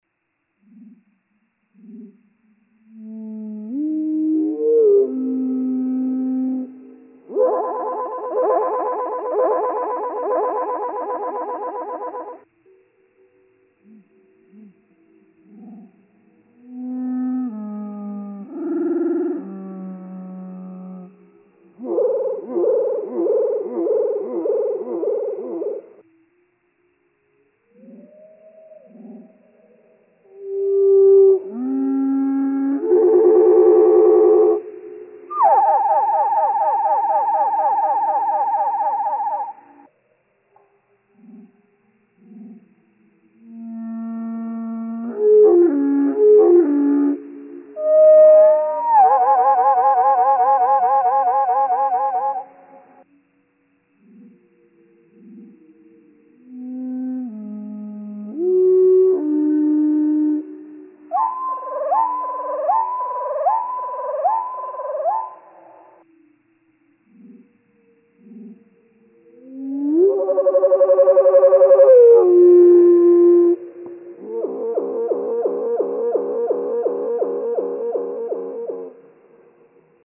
♫118. Those same six songs slowed to one-eighth speed. (1:19)
118_Wood_Thrush.mp3